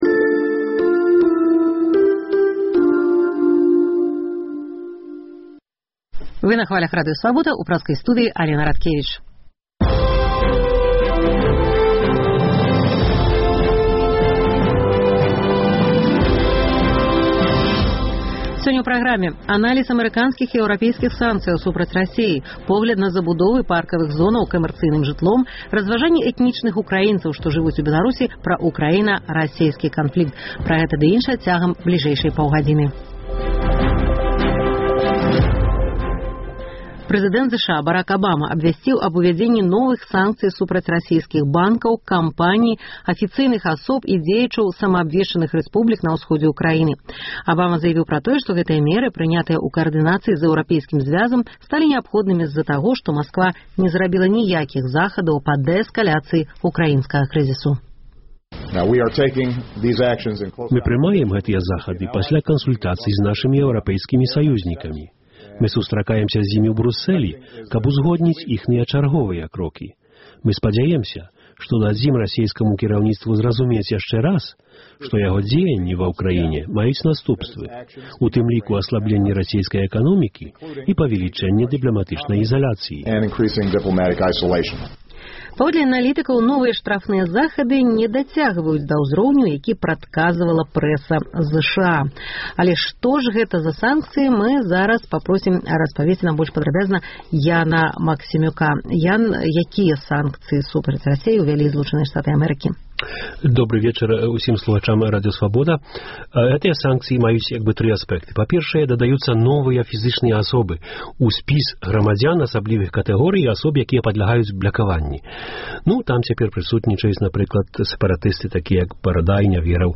Адказы даюць амэрыканскія і беларускія аналітыкі. У праграме таксама інтэрвію з украінскім дэпутатам і пісьменьнікам Аляксандрам Брыгінцом, якога абвясьцілі ў Менску пэрсонай нон-грата, погляд на праблему банкруцтваў прыватных прадпрыемстваў і на забудовы паркавых зонаў камэрцыйным жыльлём, разважаньні з нагоды юбілею канцлера ЗША Ангелы Мэркель.